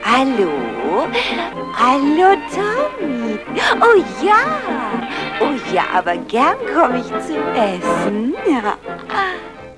- d.Cast: Erzähler: Arnold Marquis, Mammy: (?????), Toots: (